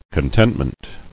[con·tent·ment]